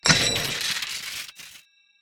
Casino Hit Big Money
Ahh Cash Casino Coin Goofy Irony Mobile Money sound effect free sound royalty free Memes